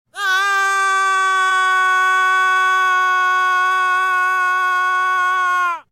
dbd steve harrington scream Meme Sound Effect
This sound is perfect for adding humor, surprise, or dramatic timing to your content.
dbd steve harrington scream.mp3